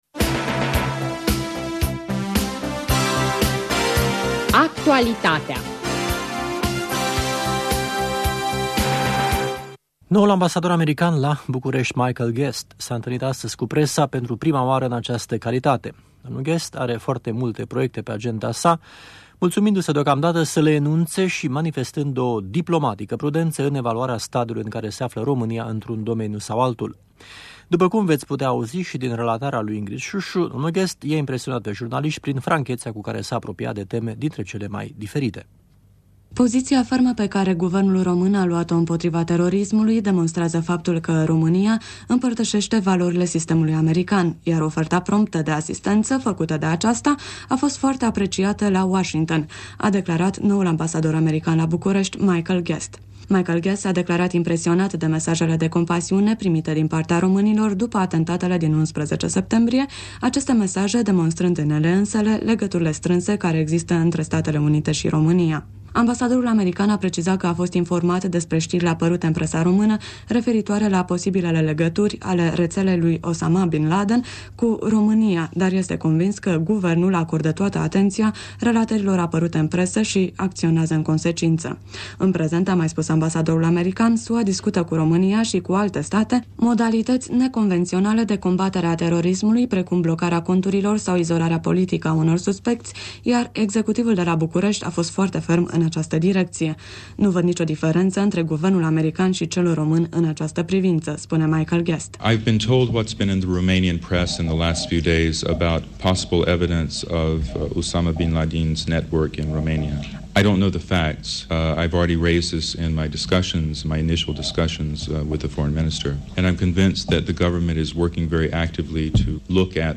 Conferința de presă a noului ambasador american la București